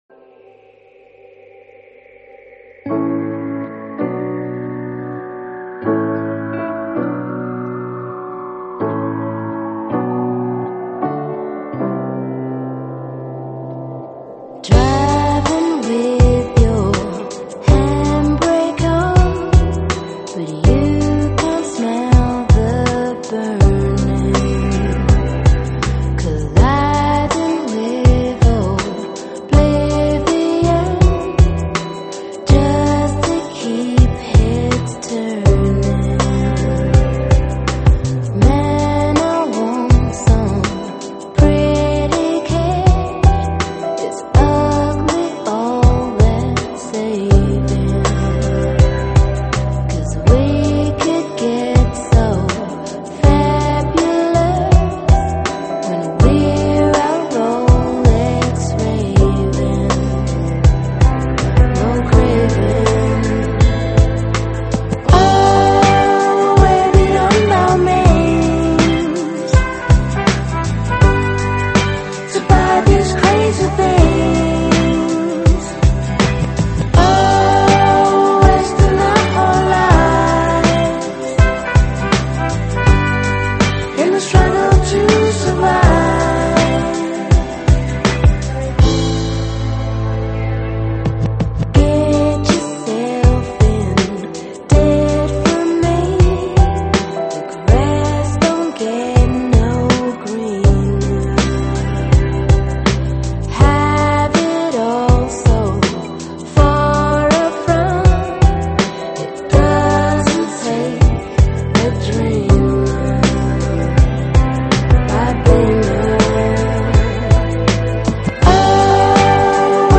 英文舞曲